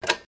rtc_pickup.mp3